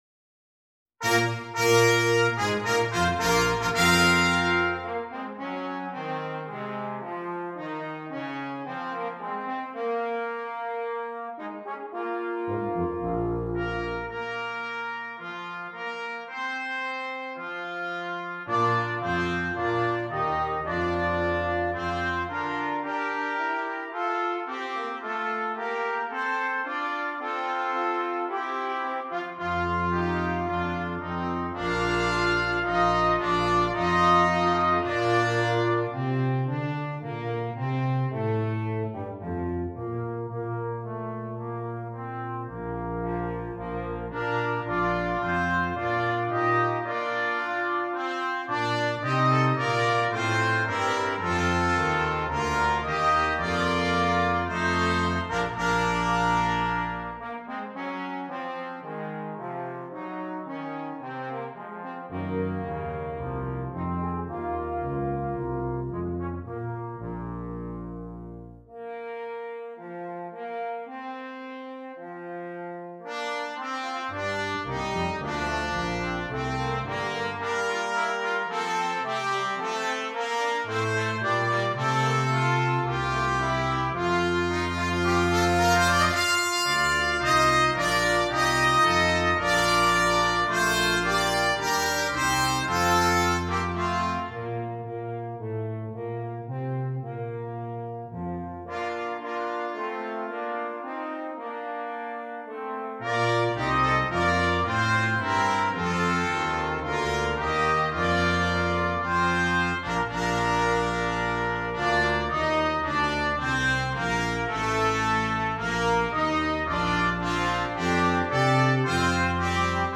Christmas
Brass Quintet